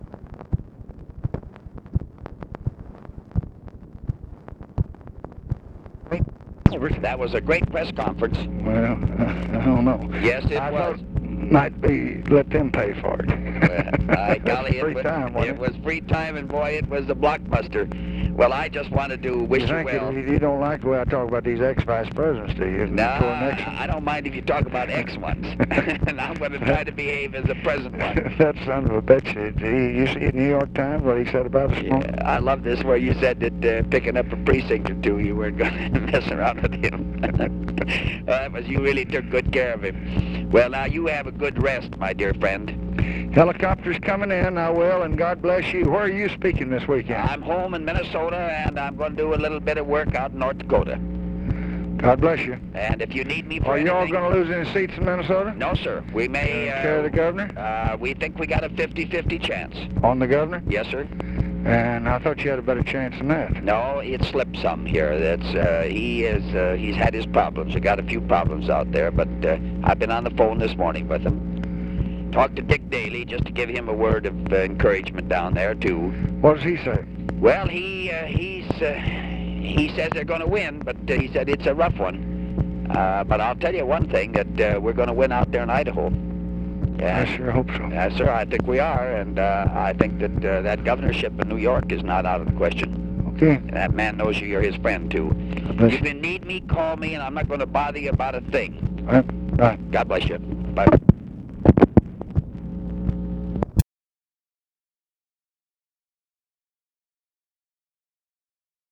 Conversation with HUBERT HUMPHREY, November 4, 1966
Secret White House Tapes